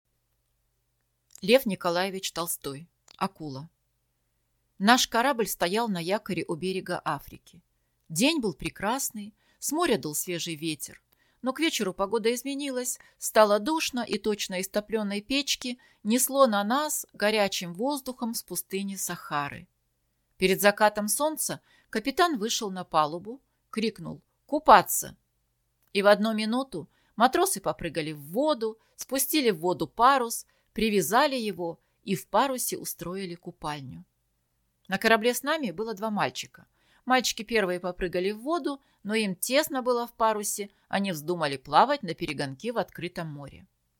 Аудиокнига Акула | Библиотека аудиокниг